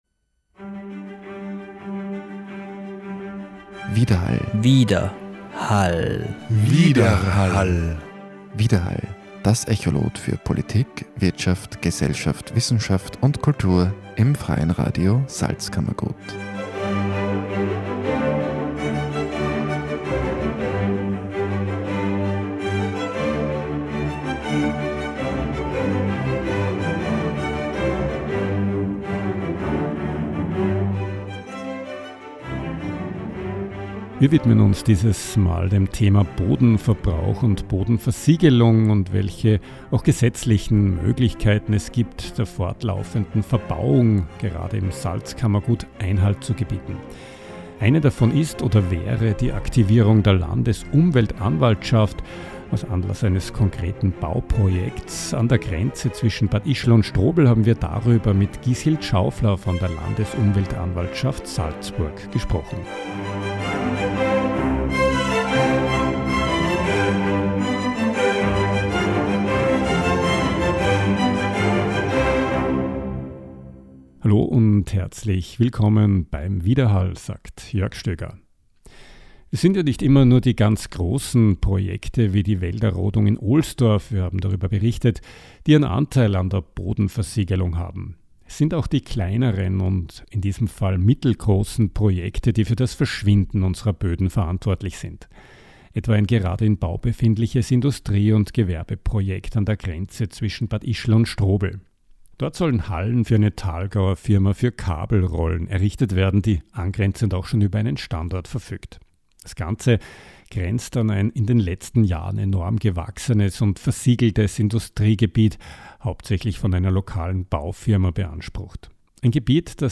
Was tun gegen Bodenverbrauch und Bodenversiegelung – Gespräch mit der Salzburger Umweltanwältin Gishild Schaufler